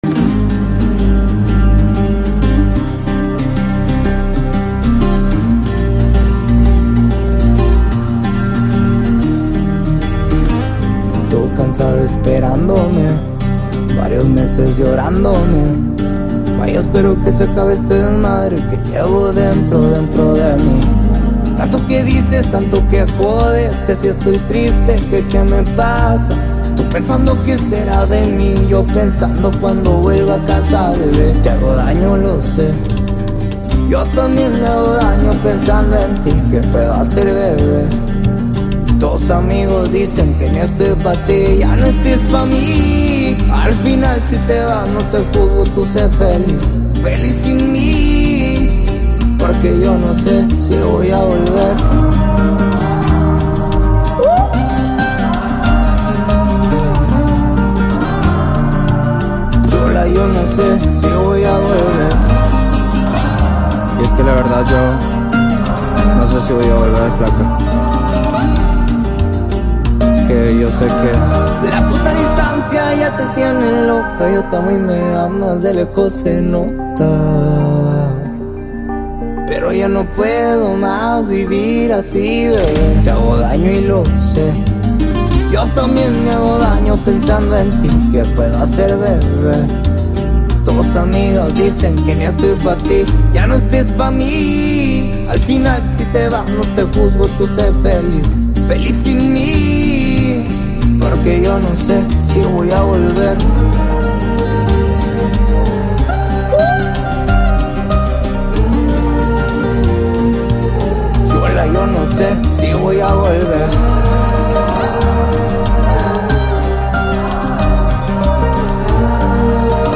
a smooth and engaging tune
It delivers a sound that feels both fresh and consistent.